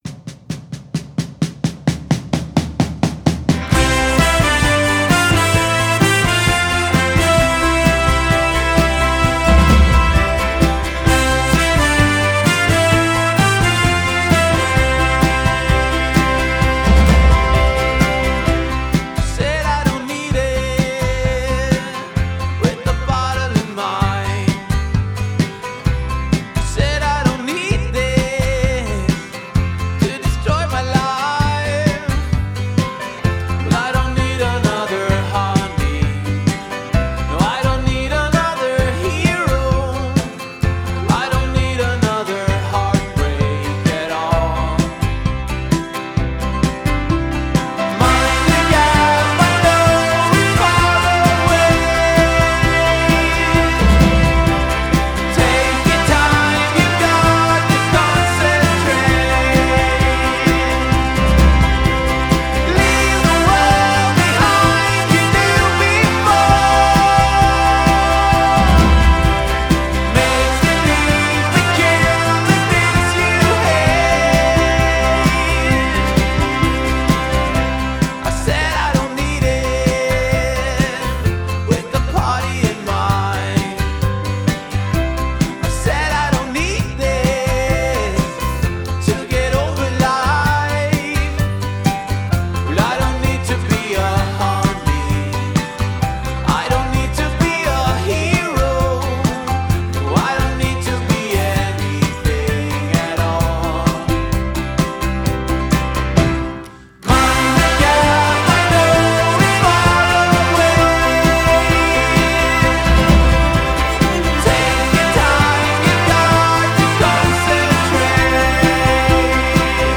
Genre: Indie-Pop / Folk